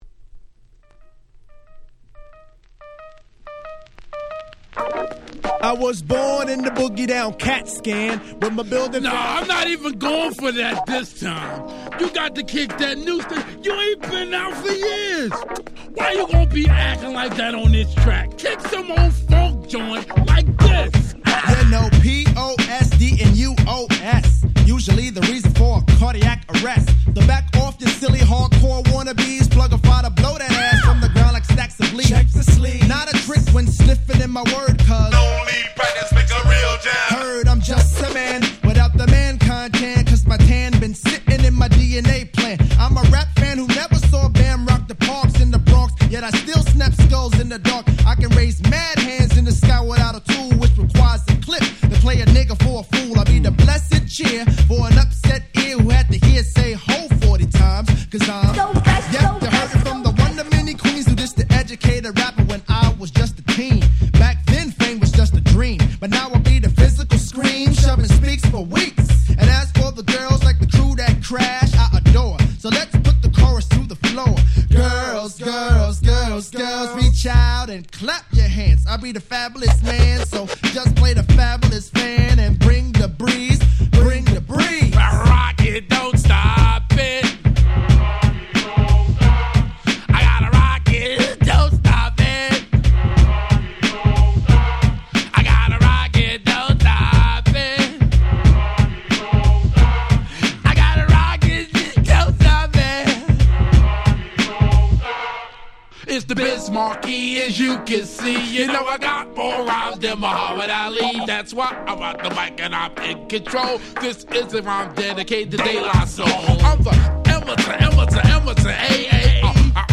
93' Smash Hit Hip Hop !!
90's Boom Bap